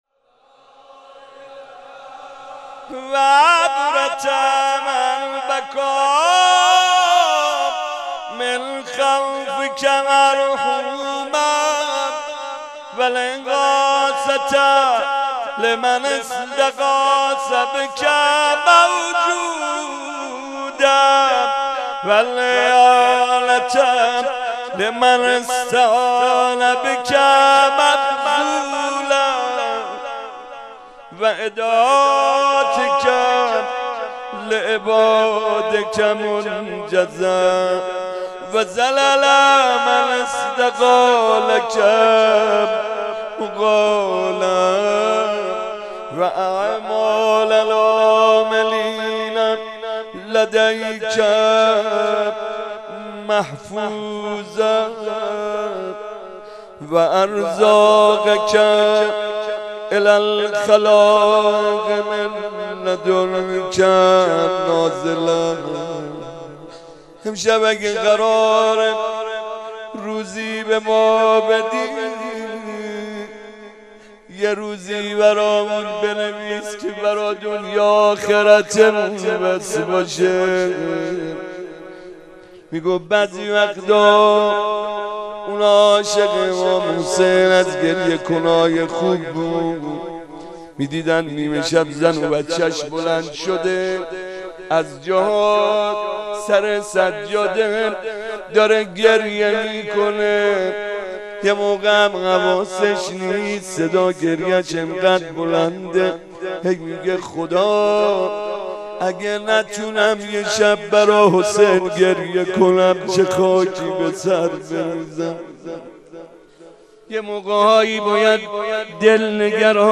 مناجات
مناسبت : شب بیستم رمضان
قالب : مناجات